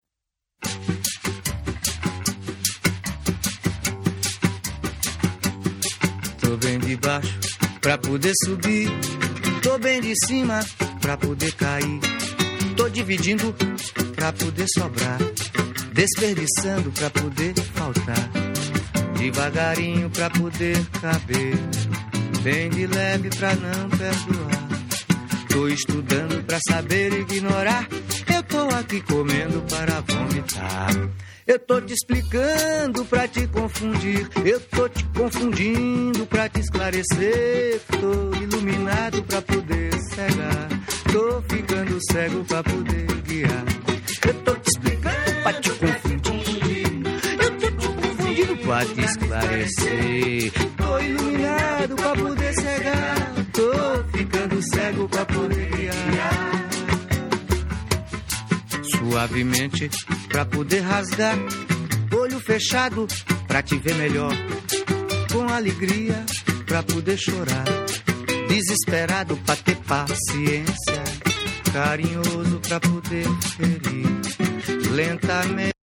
キューバで誕生したルンバがスペインのジプシーがフラメンコと融合させた、ルンバ・カタラーナのコンピレーション。
WORLD / CD